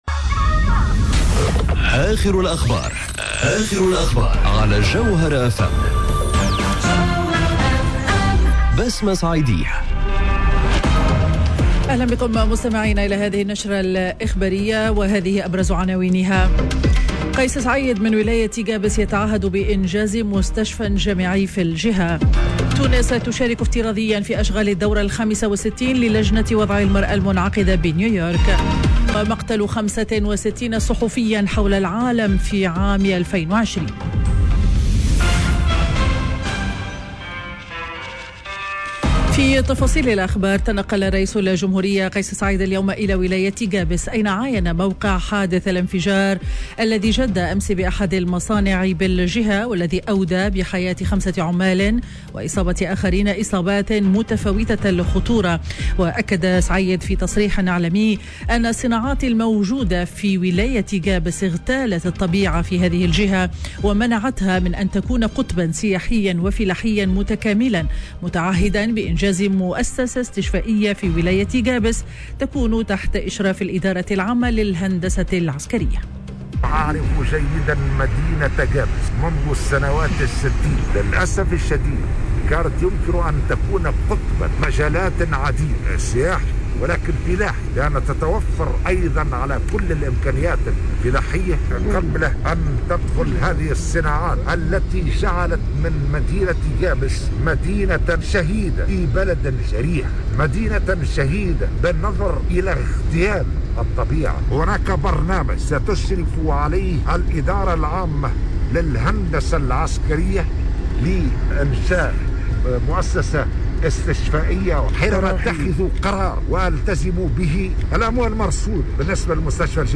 نشرة أخبار منتصف النهار ليوم الأحد 14 مارس 2021